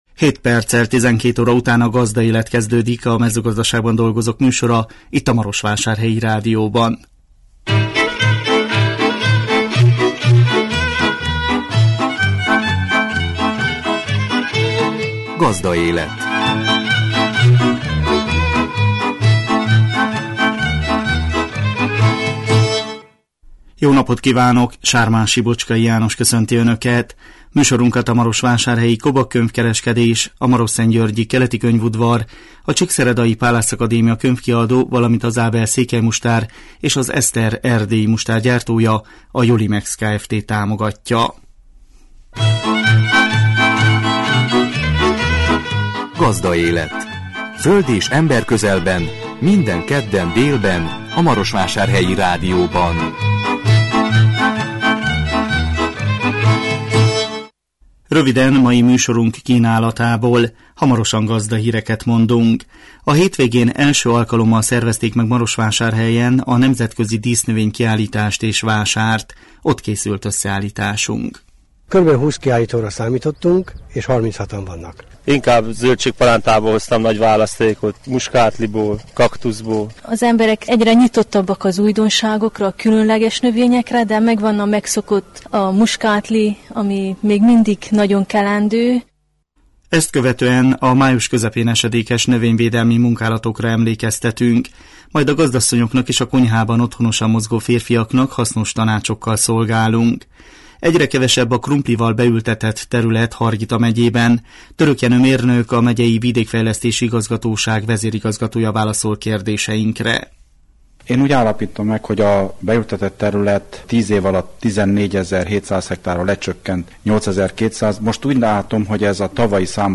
A 2017 május 9-én jelentkező műsor tartalma: Gazdahírek, A hétvégén, első alkalommal szervezték meg Marosvásárhelyen a nemzetközi dísznövény kiállítást és vásárt. Ott készült összeállításunk. Ezt követően a május közepén esedékes növényvédelmi munkálatokra emlékeztetünk.